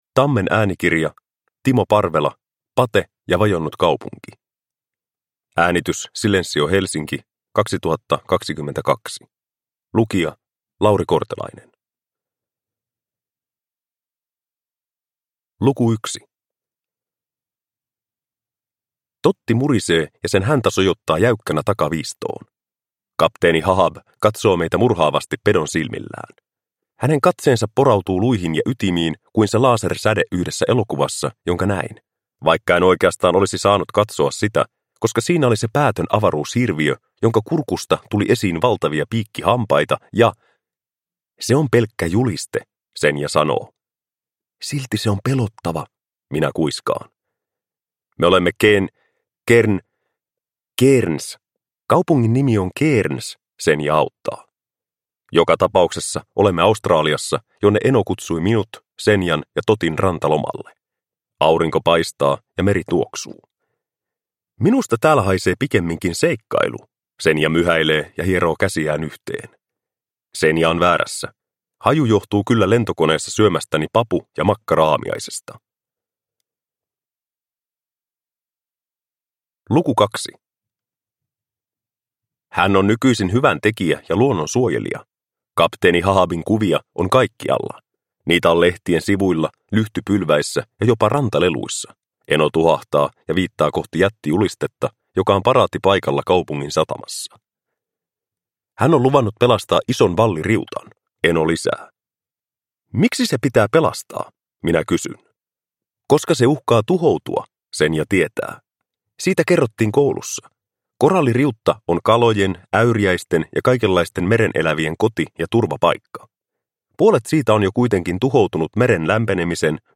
Pate ja vajonnut kaupunki – Ljudbok – Laddas ner